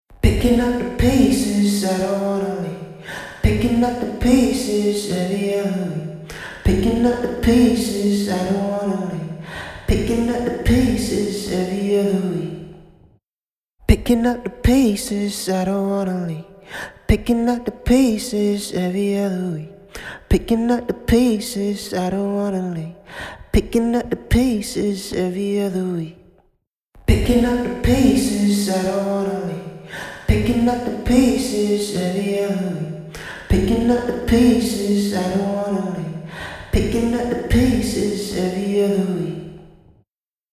Octavox | Vocals | Preset: Unnatural Room
Octavox-Eventide-Male-Vocal-Unnatural-Room-2.mp3